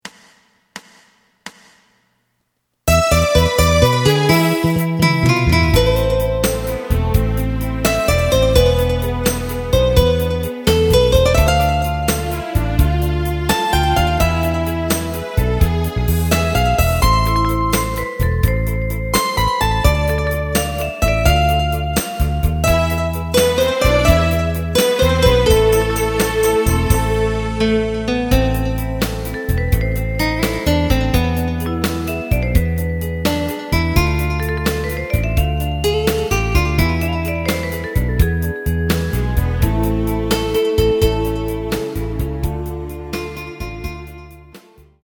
エレキバンド用カラオケCD製作・販売
すべての主旋律を１人で演奏するスタイルにアレンジしてみました。
●フルコーラス(デモ演奏) メロディライン＋伴奏が演奏されます。